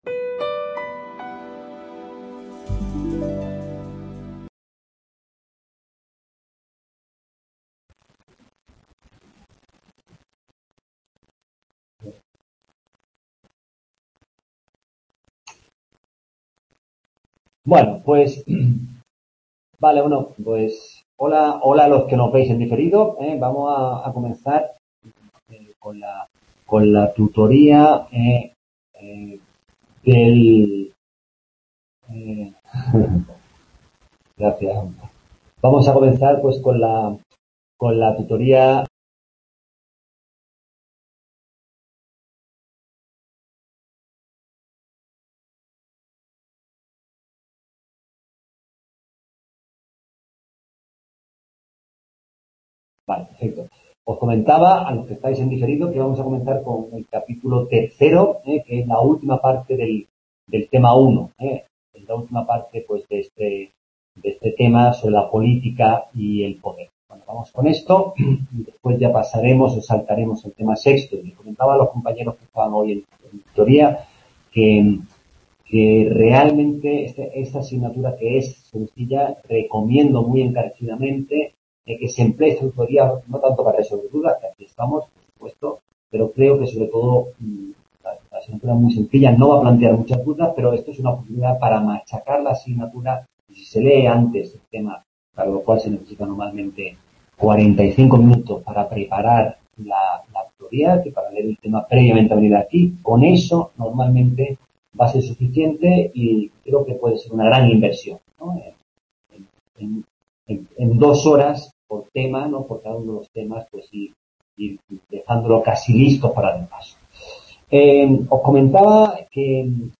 En la primera parte de esta tutoría examinamos las tres dimensiones de la política y la noción de sistema político según Easton. En la segunda parte, examinamos la noción de Estado y la génesis histórica de la forma estatal.